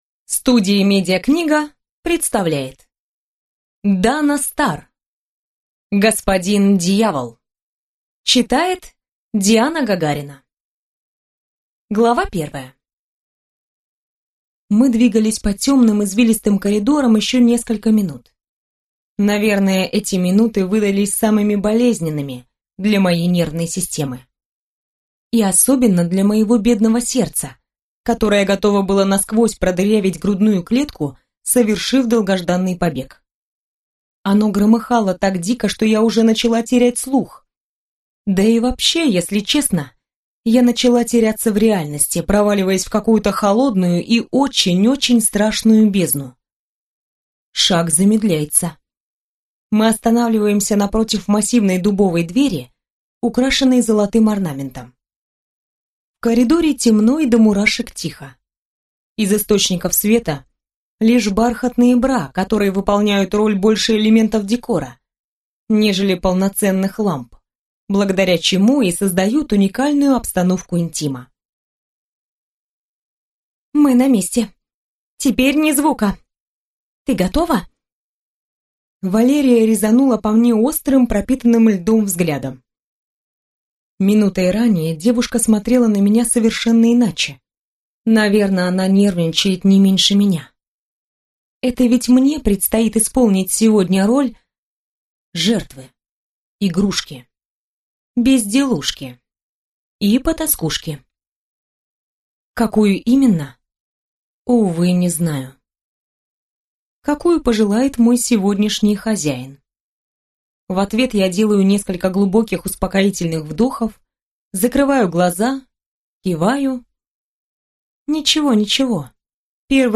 Аудиокнига Господин Дьявол | Библиотека аудиокниг
Прослушать и бесплатно скачать фрагмент аудиокниги